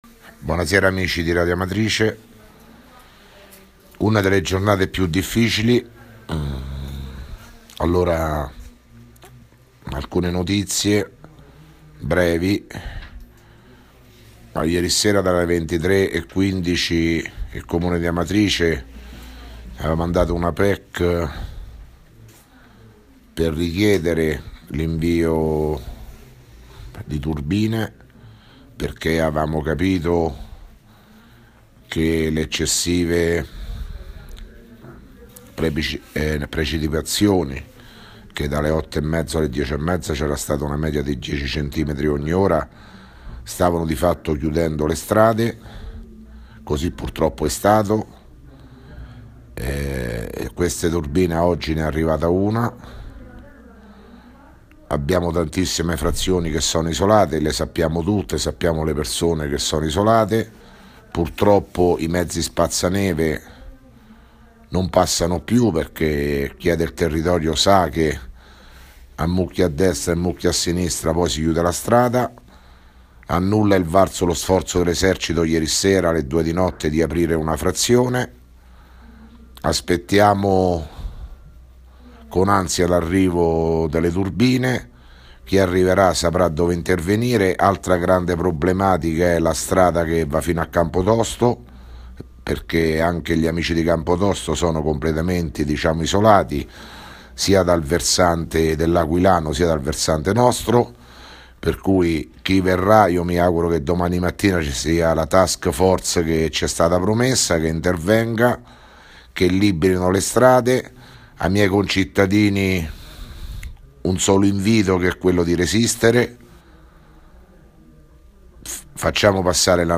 Di seguito il messaggio audio del Sindaco Sergio Pirozzi, del 18 gennaio 2017.